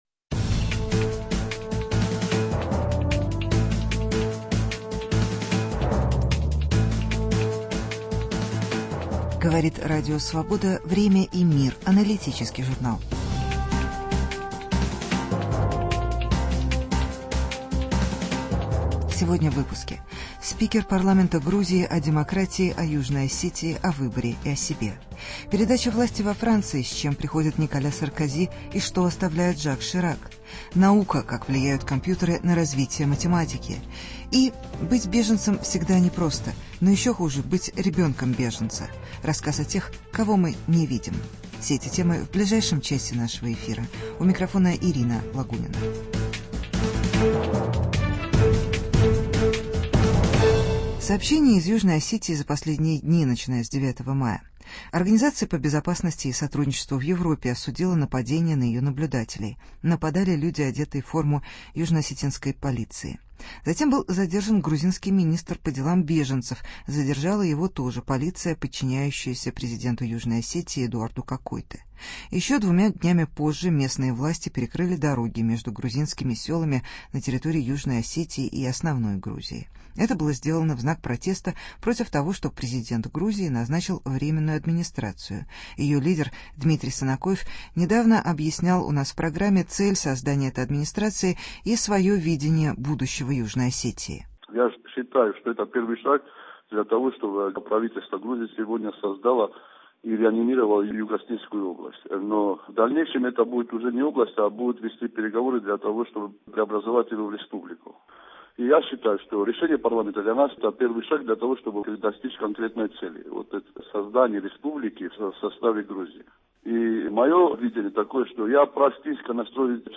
Интервью со спикером Грузинского парламента Нино Бурджанадзе.